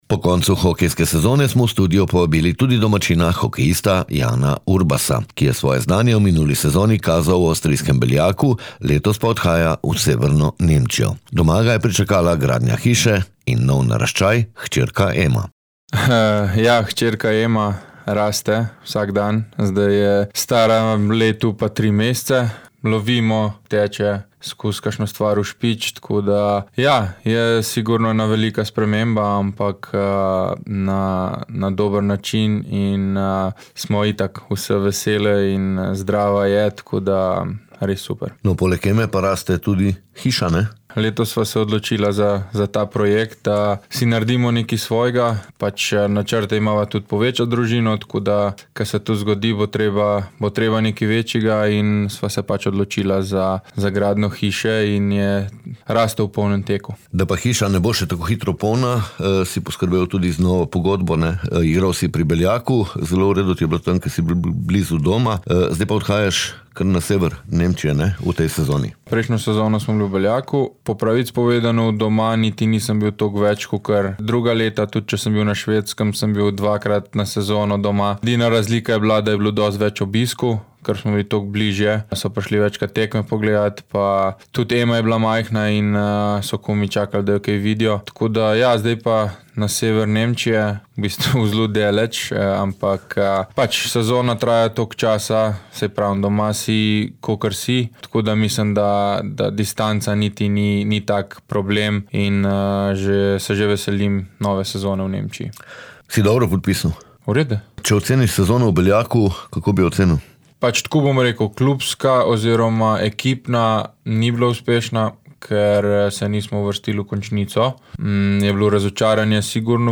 Med sezonama smo v studio povabili tudi hokejista Jan Urbasa, ki je svoje znanje v minuli sezoni kazal v avstrijskem Beljaku, že konec julija pa odhaja na sever Nemčije.